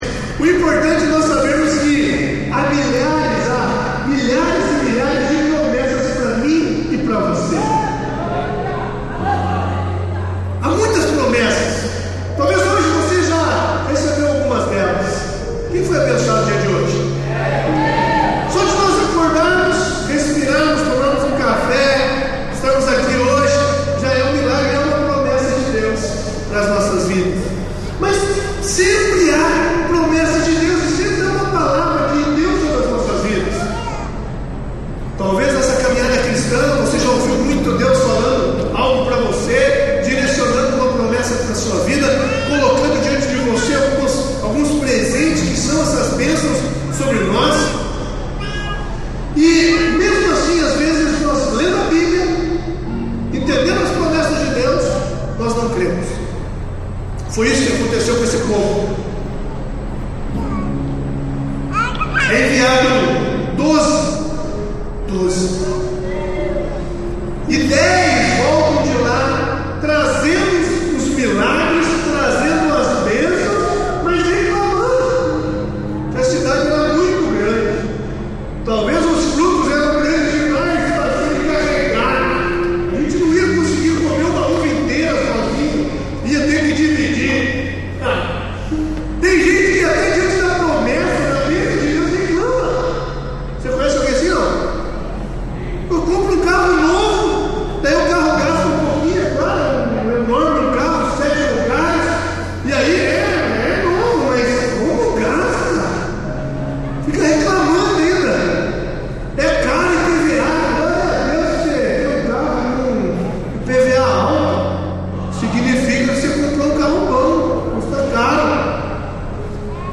ICP - Igreja Cristã Presbiteriana